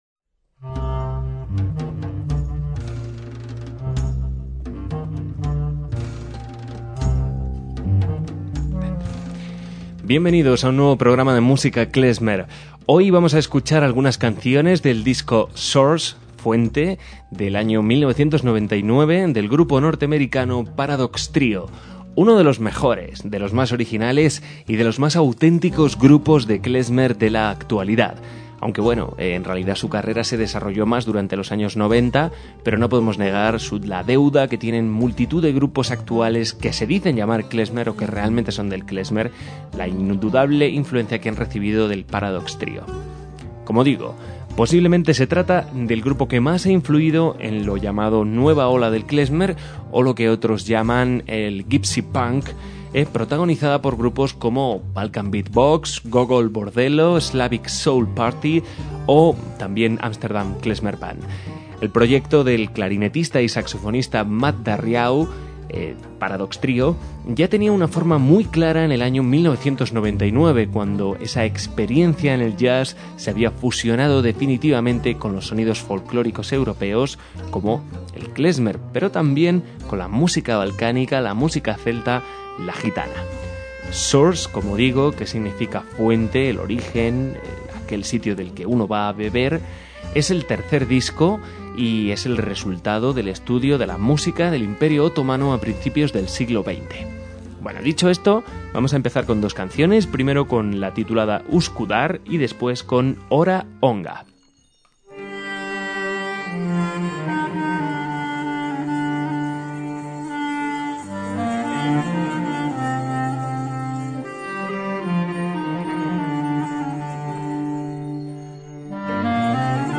MÚSICA KLEZMER
en guitarras
en chelo
en percusiones